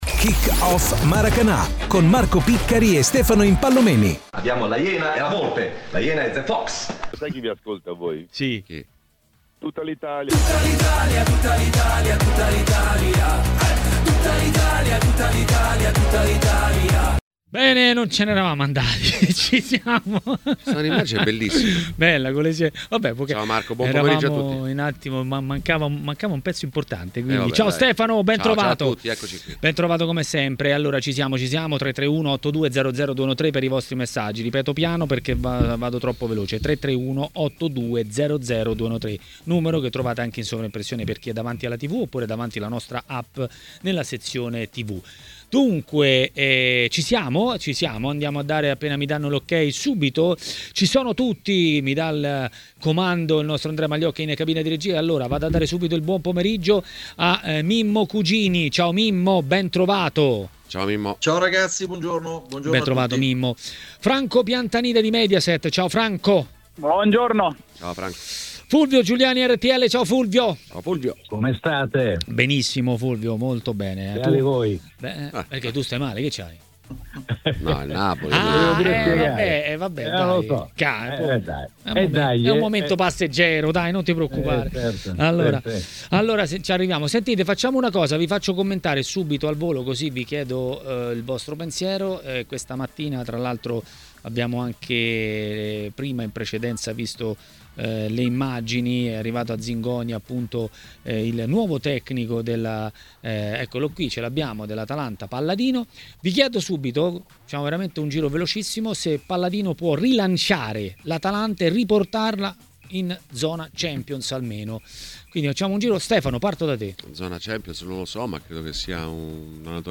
Le Interviste
è intervenuto ai microfoni di Tmw Radio nel corso della trasmissione 'Maracanà'. Palladino può rilanciare l'Atalanta?